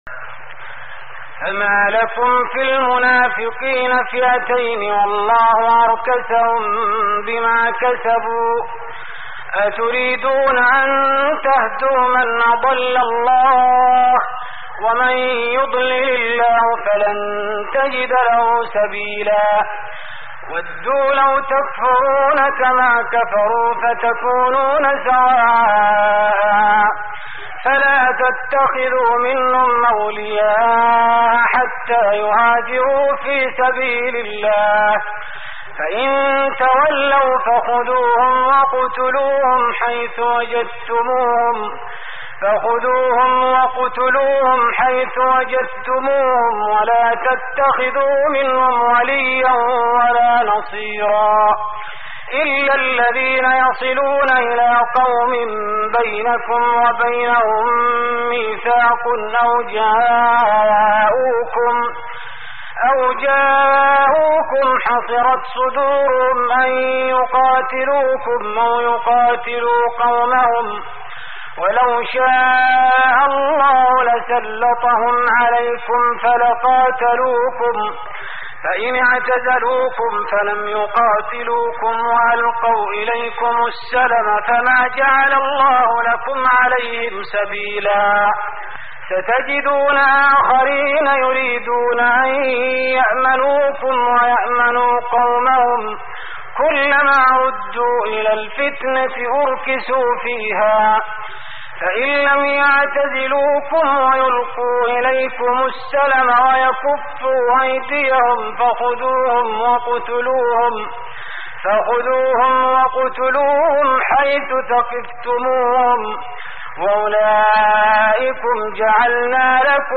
تهجد رمضان 1416هـ من سورة النساء (88-147) Tahajjud Ramadan 1416H from Surah An-Nisaa > تراويح الحرم النبوي عام 1416 🕌 > التراويح - تلاوات الحرمين